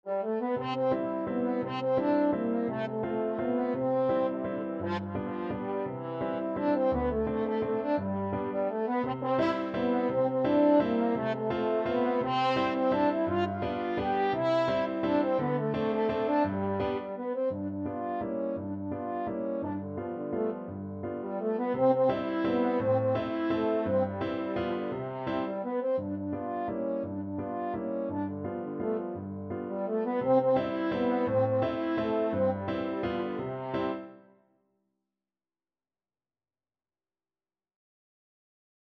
French Horn
3/4 (View more 3/4 Music)
Classical (View more Classical French Horn Music)